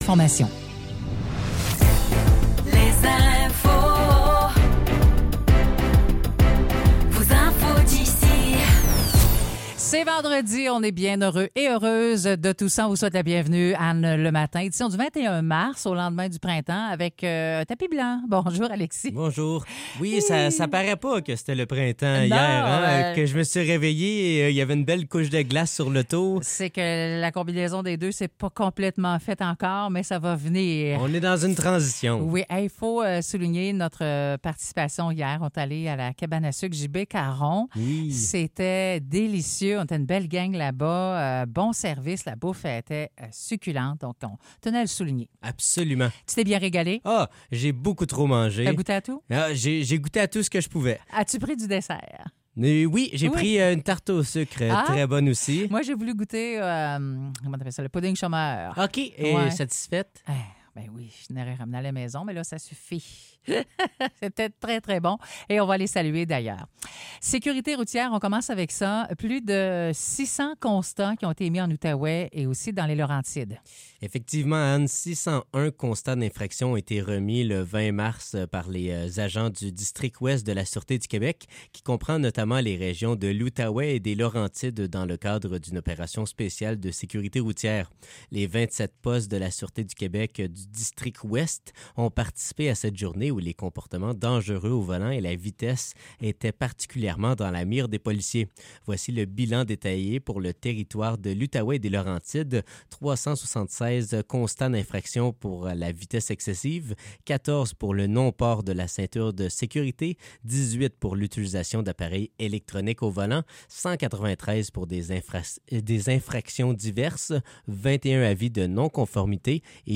Nouvelles locales - 21 mars 2025 - 9 h